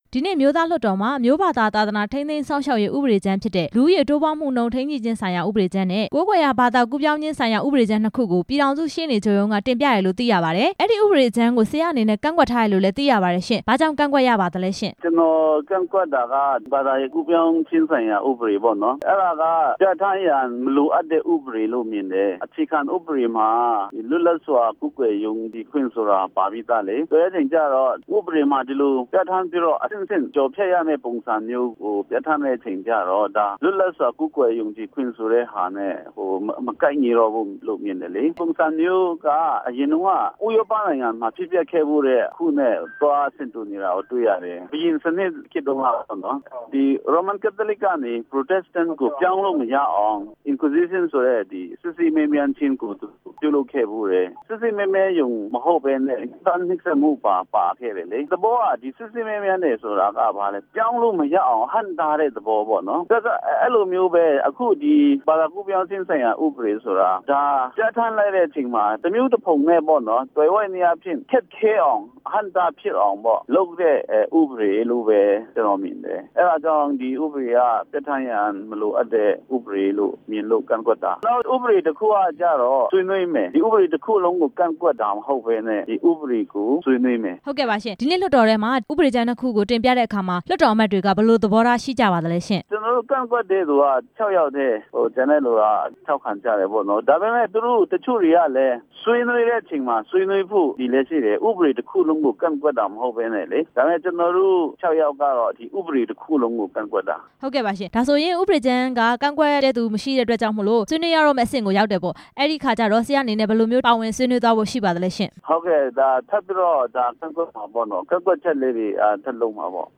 အမျိုးသားလွှတ်တော် ကိုယ်စားလှယ် ဦးဇုန်လှယ်ထန်းနဲ့ မေးမြန်းချက်